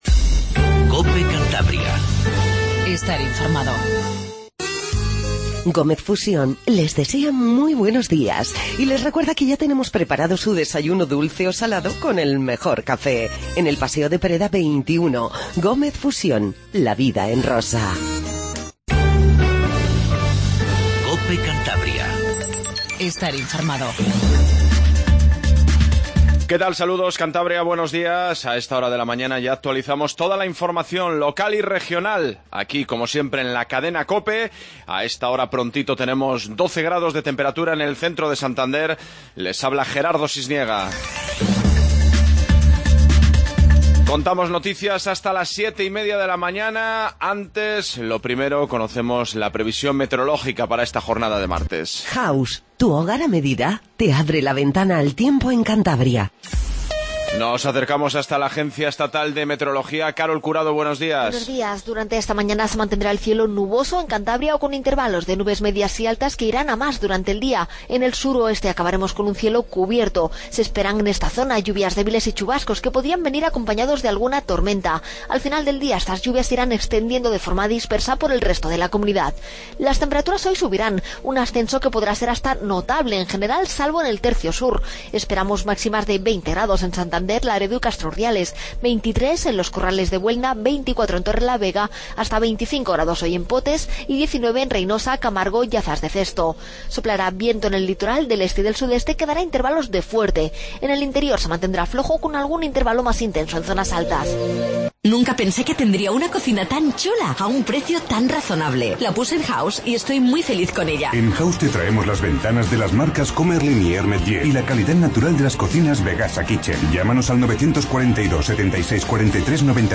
INFORMATIVO MATINAL 07:20